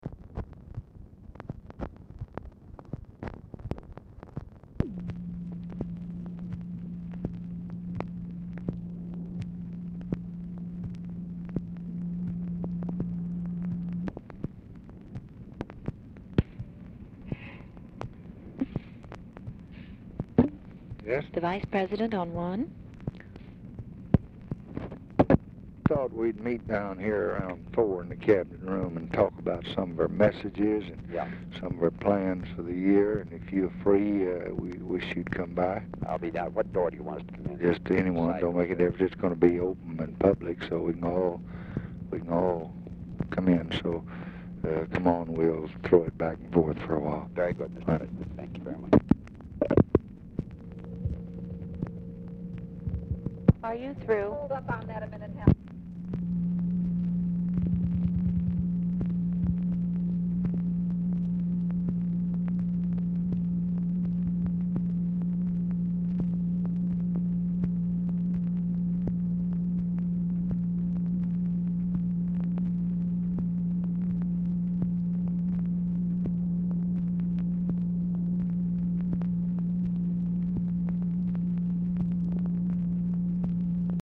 Telephone conversation # 9508, sound recording, LBJ and HUBERT HUMPHREY, 1/17/1966, 12:10PM | Discover LBJ
Format Dictation belt
Location Of Speaker 1 Oval Office or unknown location